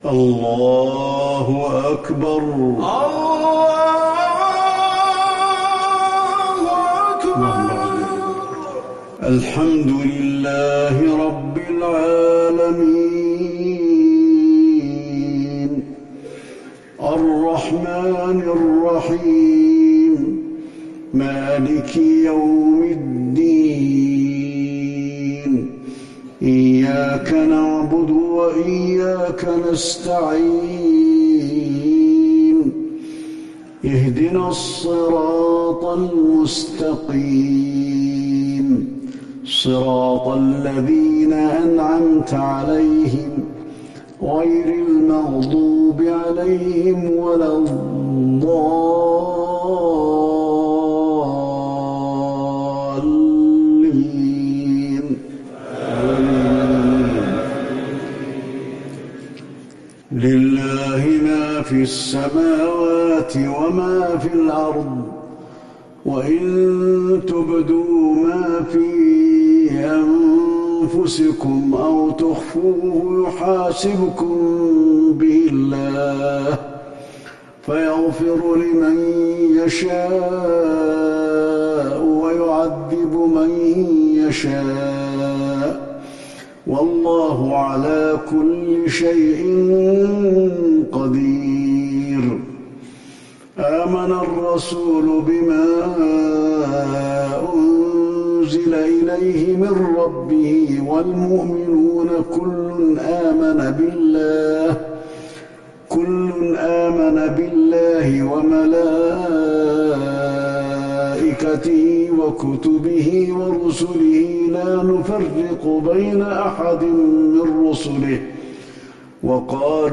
صلاة المغرب للشيخ علي الحذيفي 7 جمادي الآخر 1441 هـ
تِلَاوَات الْحَرَمَيْن .